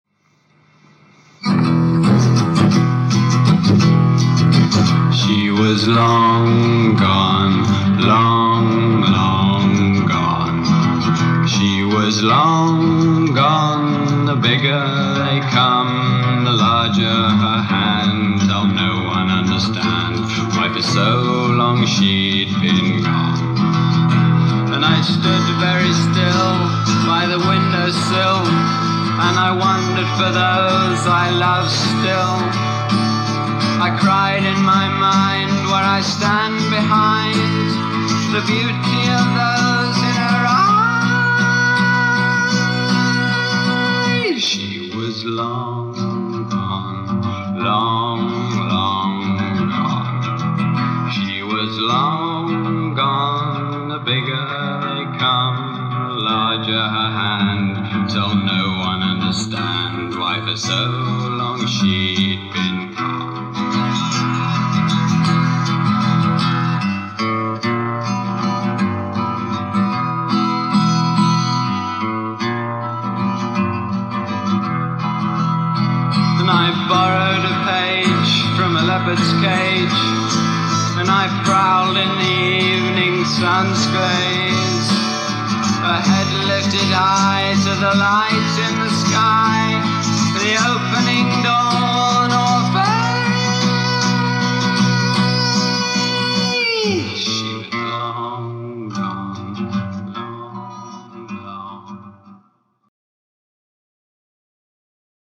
Sounding like an early, stripped back demo version.